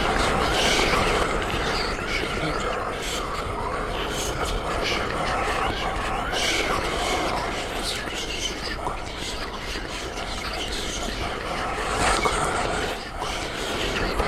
Whispers1.ogg